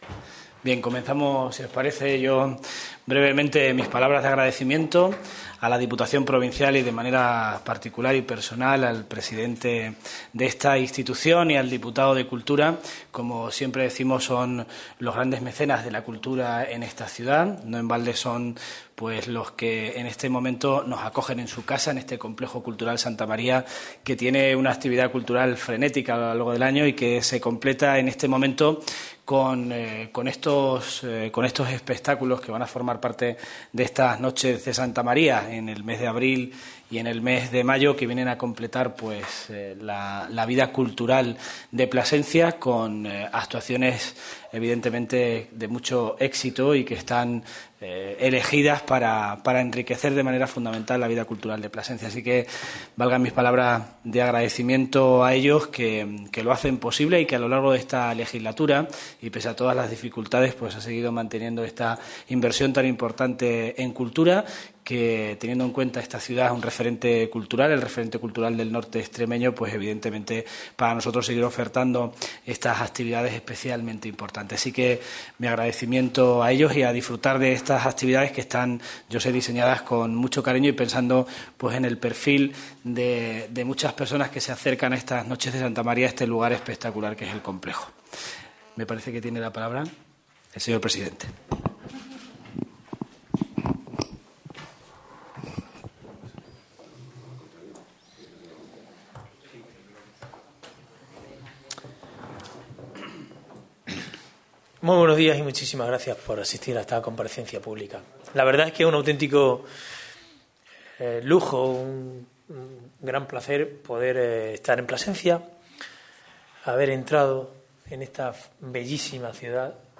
CORTES DE VOZ
El presidente de la Diputación de Cáceres, Laureano León Rodríguez, acompañado por el alcalde de Plasencia, Fernando Pizarro García-Polo, y por el diputado de Cultura, Eduardo Villaverde Torrecilla, ha presentado este martes en Plasencia la XIII edición del ciclo cultural ‘Noches de Santa María’, organizado por la Institución Cultural ‘El Brocense’ de la Diputación.